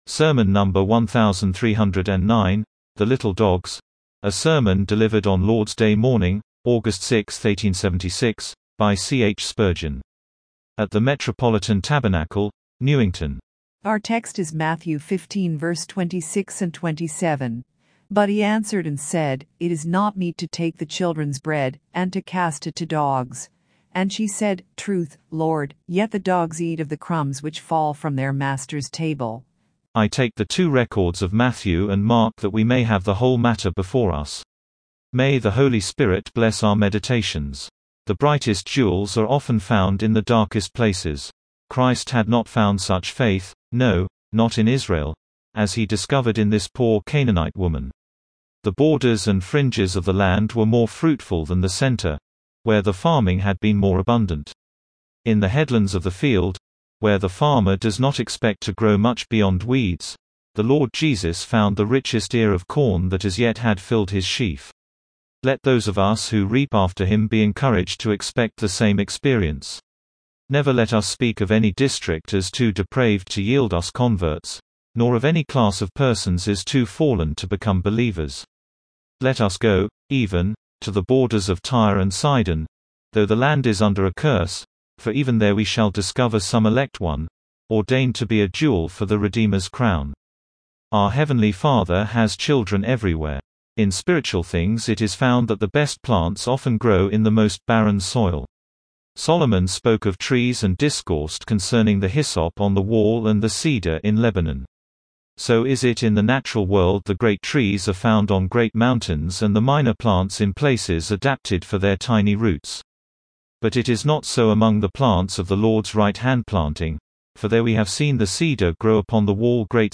Sermon number 1,309, THE LITTLE DOGS